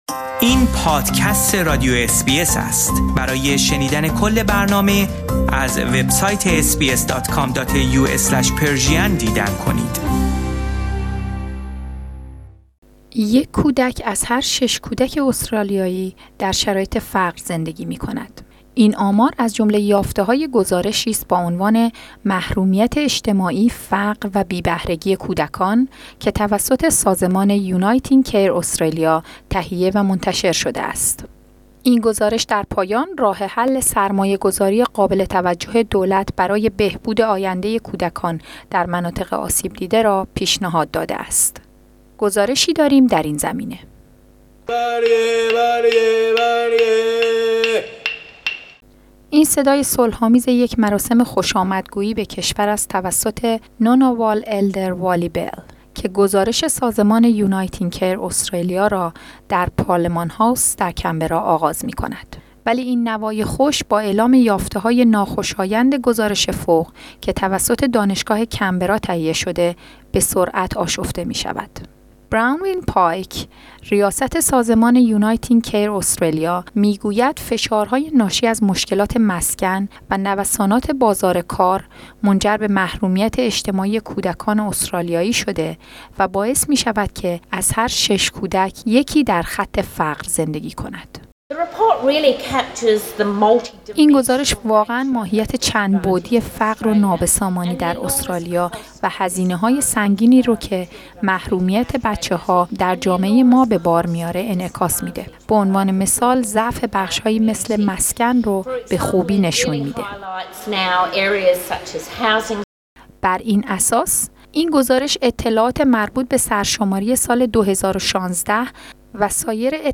این صدای صلح آمیز یک مراسم خوش آمدگویی به کشور است توسط